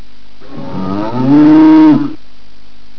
Click here to hear the oxen!
moo.wav